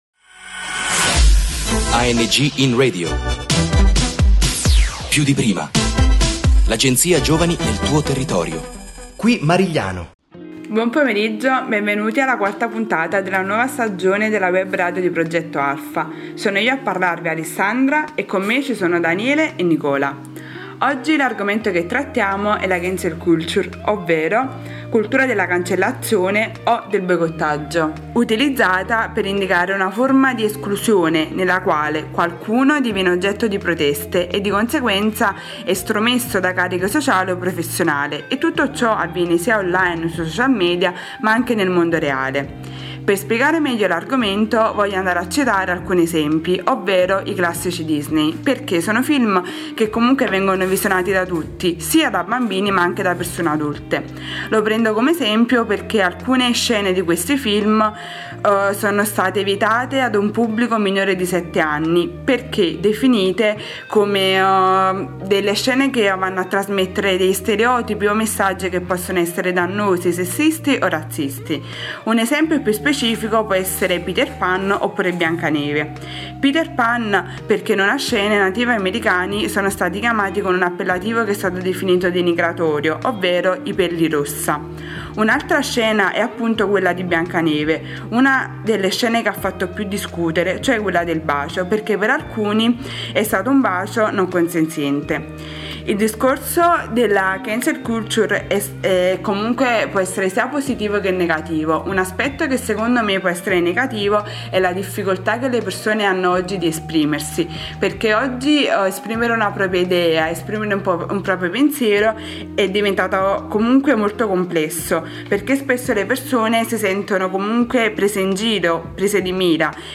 15 Mag ANGinRadio – Marigliano – Cancel culture e politicamente corretto: un confronto tra giovani